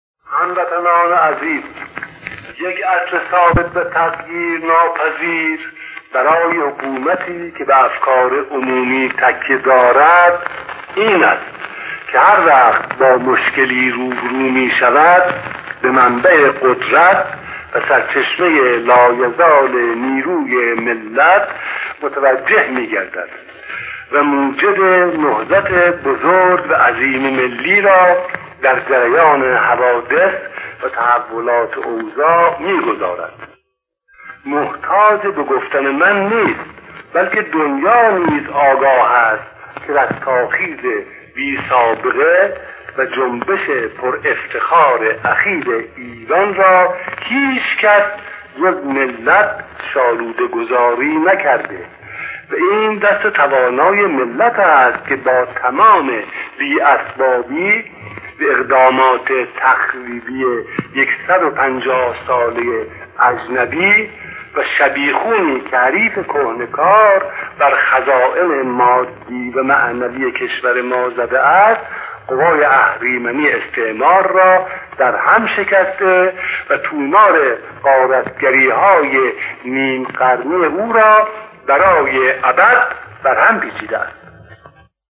انتشار صوت سخنرانی‌های تاریخی
نطق‌های آتشین دکتر محمد مصدق و آیت‌الله ابوالقاسم کاشانی در دوره هفدهم مجلس شورای ملی و درباره صنعت نفت از جمله سخنان تاریخی‌ ادوار مختلف مجلس است.
این سخنان در دوره هفدهم مجلس شورای ملی، بین سال‌های 1330 تا 1332 و در جریان بحث‌های مربوط به ملی شدن صنعت نفت ایران بیان شده است.